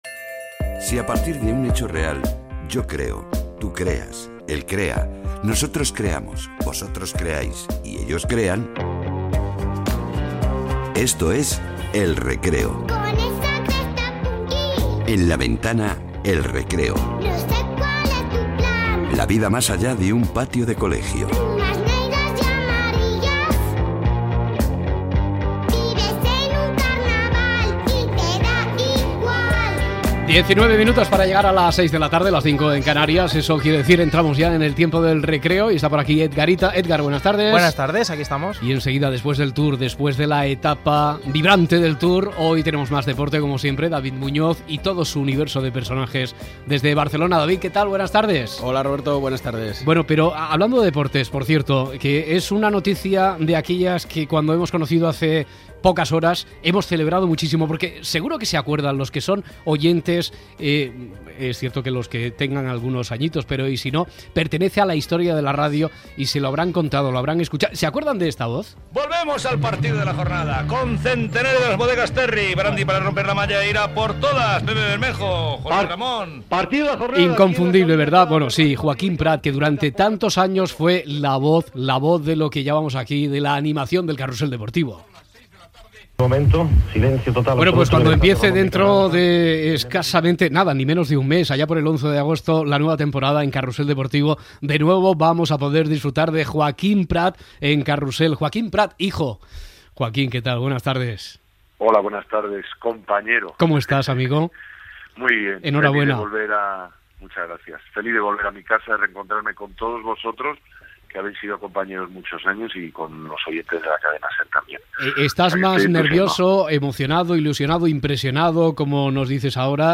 Secció "El recreo" amb una entrevista a Joaquín Prat Sandberg que serà l'animador del programa "El carrusel deportivo"
Entreteniment